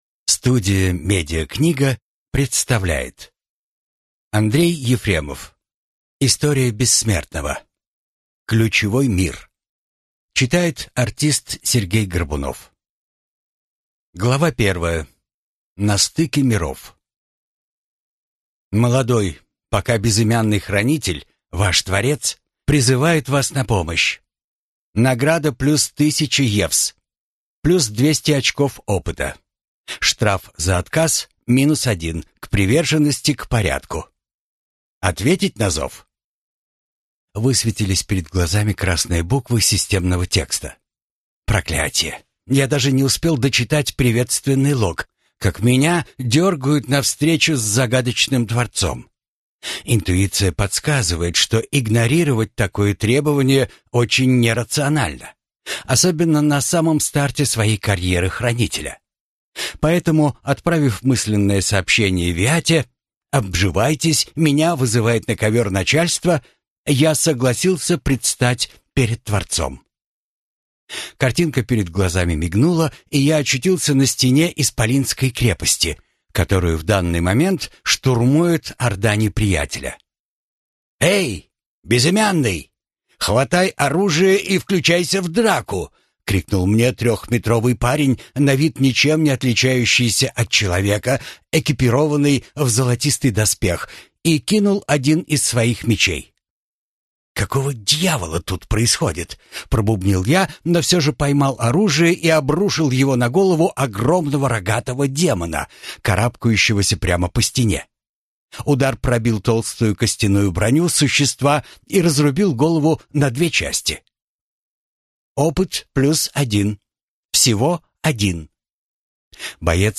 Аудиокнига История Бессмертного 8. Ключевой мир | Библиотека аудиокниг